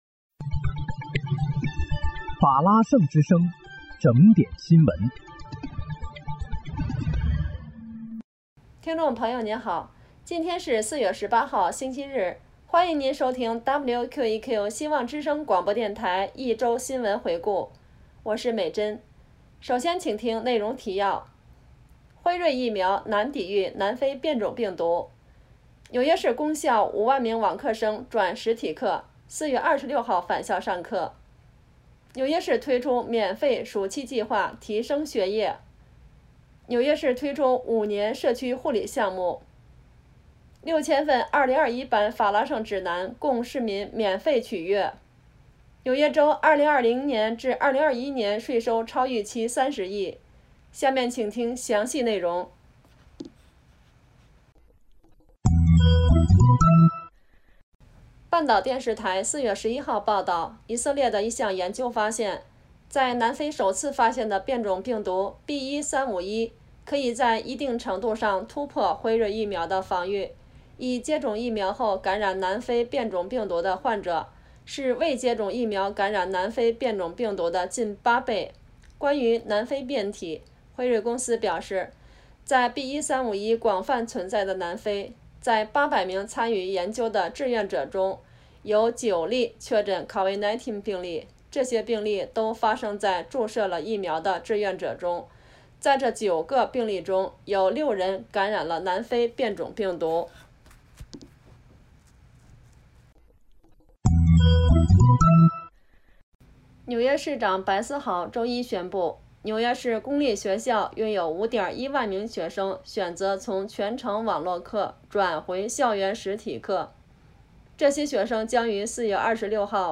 4月18日（星期日）一周新闻回顾